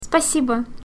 Звуки женских голосов
Женский голос произносит спасибо